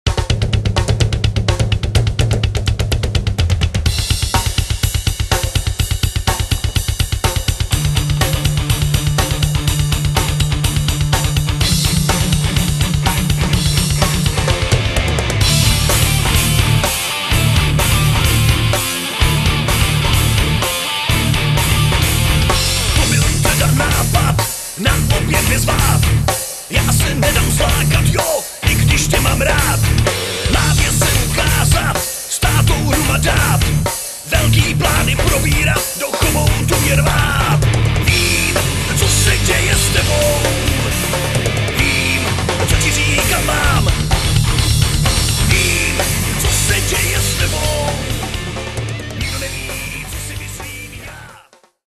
zpěv
kytara
bicí